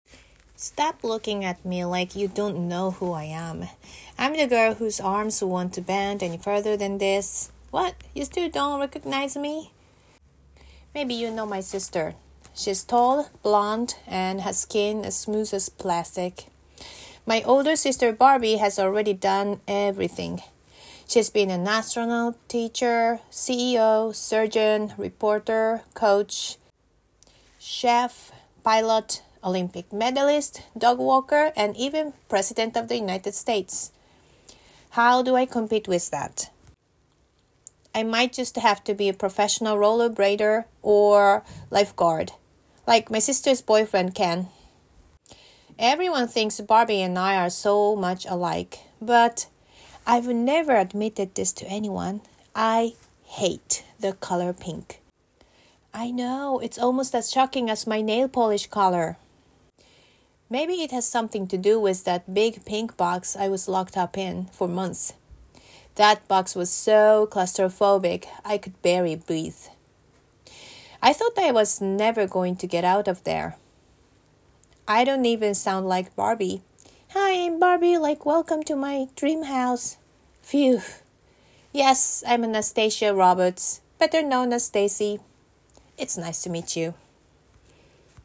Genre: Comedy